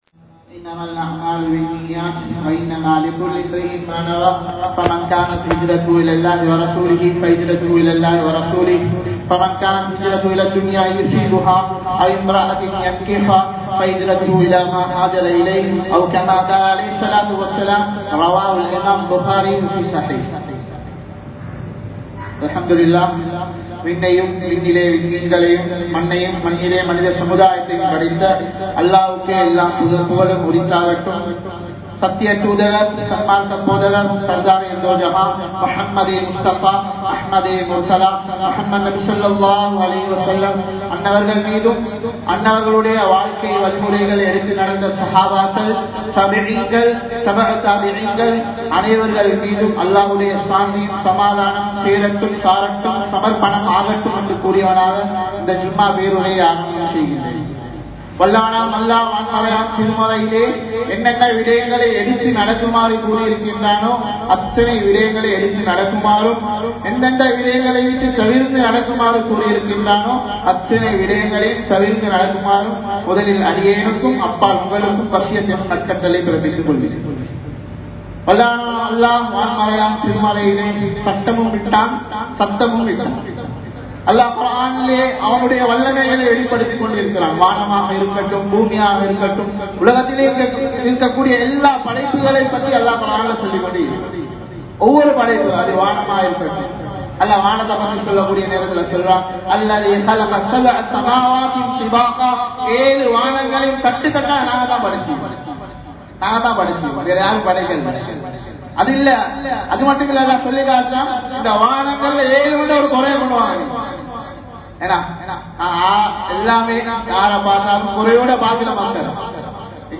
பரக்கத் என்றால் என்ன? | Audio Bayans | All Ceylon Muslim Youth Community | Addalaichenai
Muhiyadeen Jumua Masjith